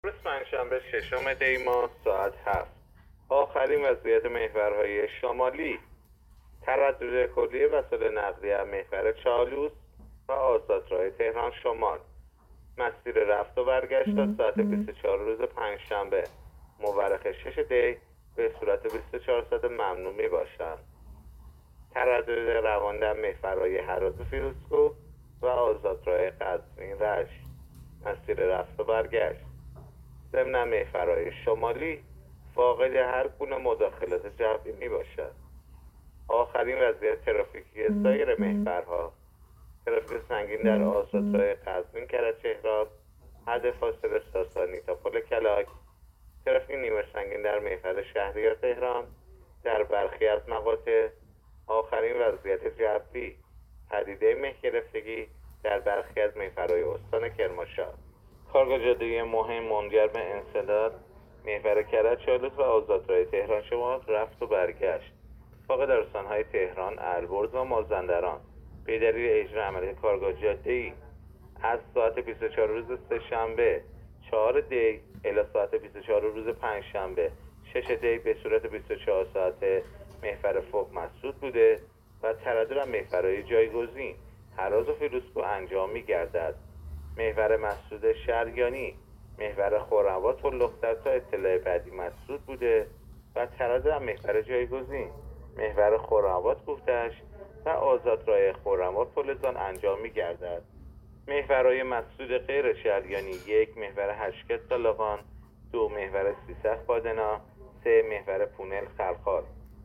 گزارش رادیو اینترنتی از آخرین وضعیت ترافیکی جاده‌ها تا ساعت ۹ ششم دی